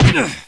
sounds: add jump and ouch sounds for Doom Legacy ( #1260 )